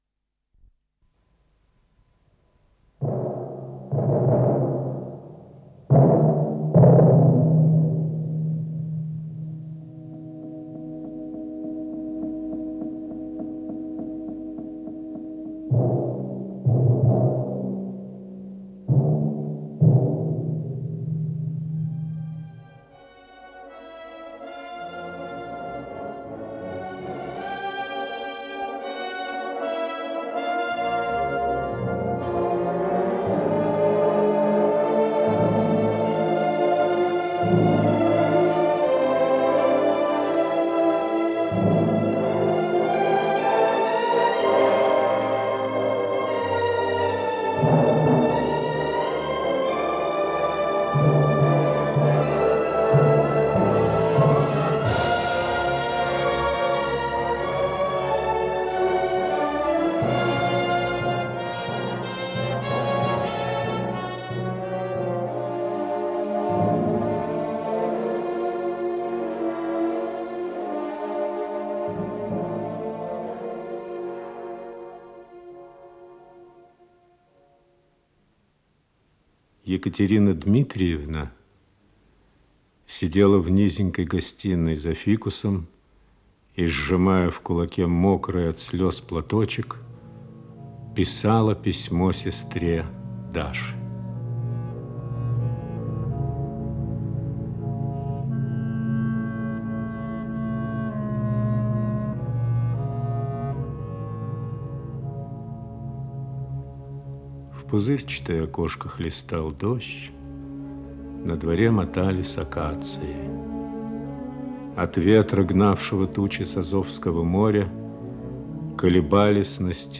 Исполнитель: Артисты московских театров
инсценированные страницы трилогии